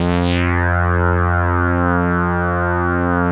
Index of /90_sSampleCDs/InVision Interactive - Keith Emerson - The Most Dangerous Synth and Organ/ORGAN+SYNTH1
41-SAWRESDRY.wav